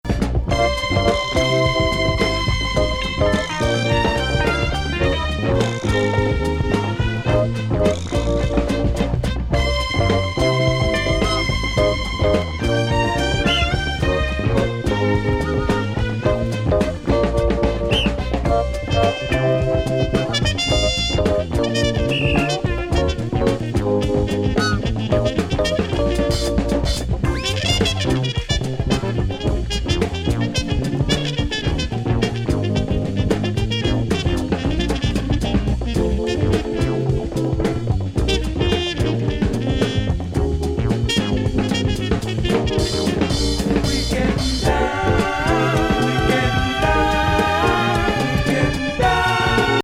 音頭的打楽器も良いアクセントの
エレピ・メロウ・レア・グルーブ